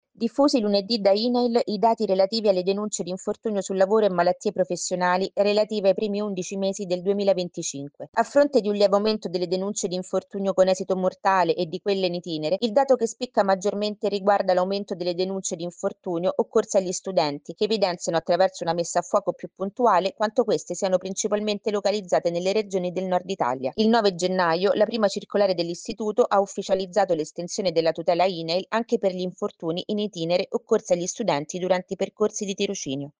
Sicurezza quotidiana – Le denunce di infortunio degli studenti sono cresciute rispetto allo scorso anno. Il servizio